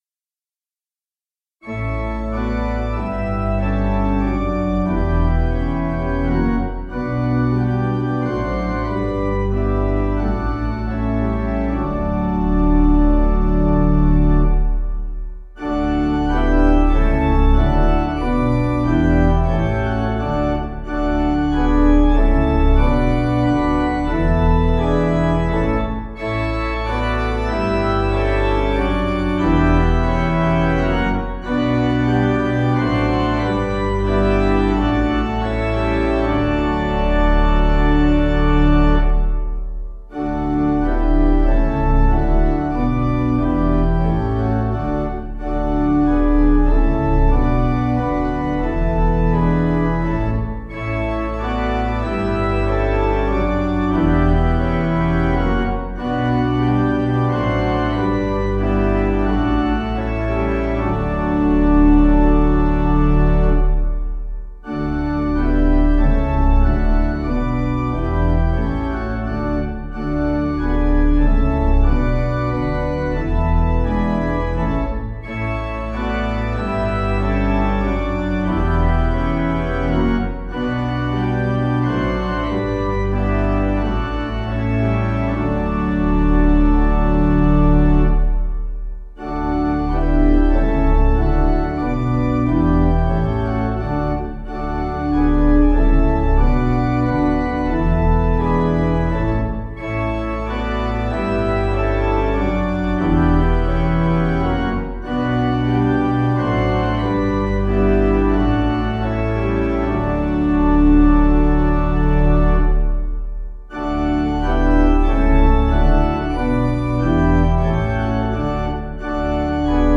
Audio files: MIDI,
Meter: 8.8.8.8
Key: D Major